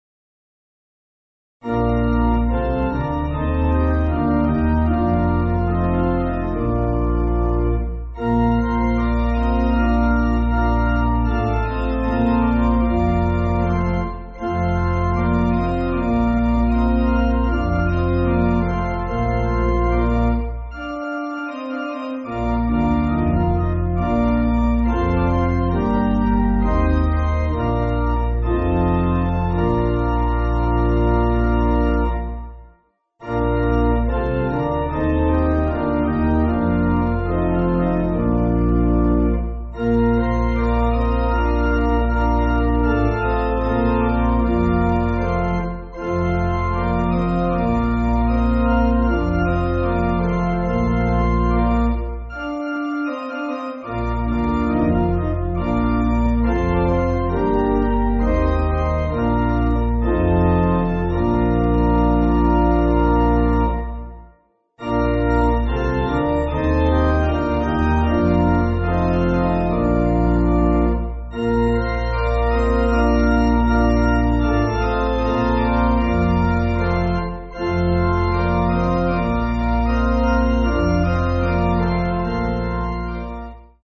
Organ
(CM)   4/Bb